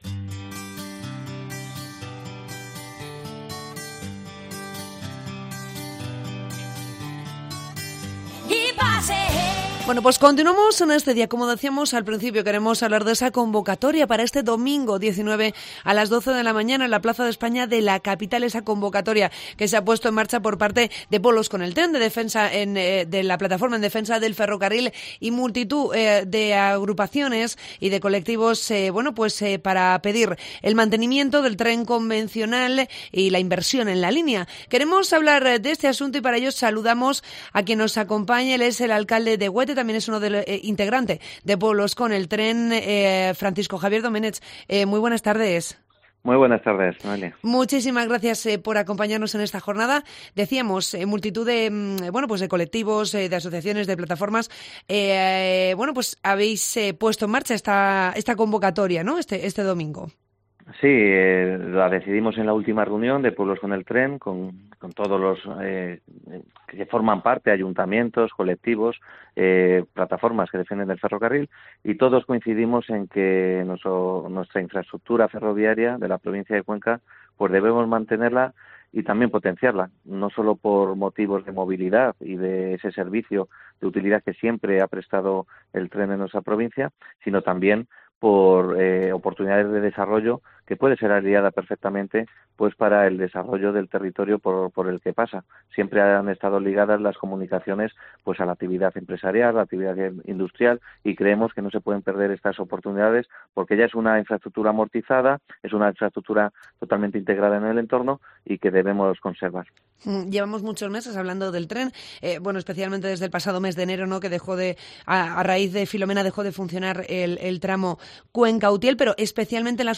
Entrevista con el alcalde de Huete, Francisco Javier Doménech